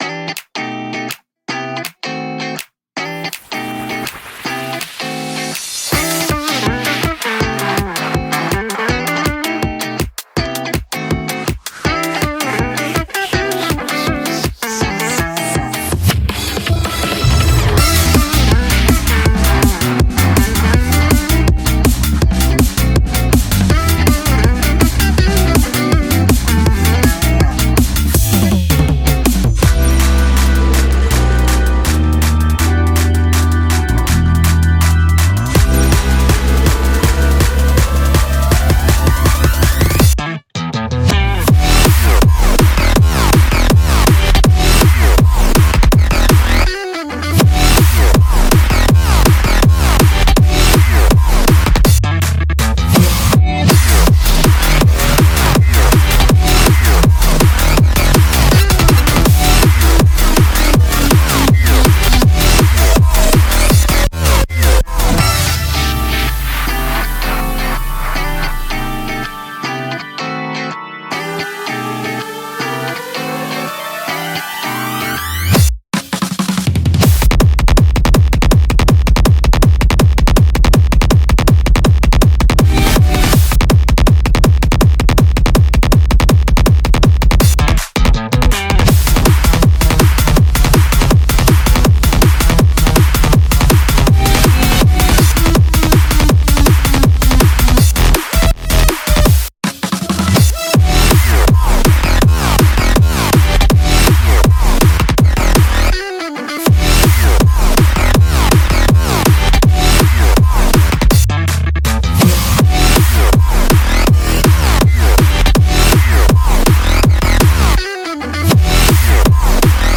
BPM162-162
Audio QualityPerfect (High Quality)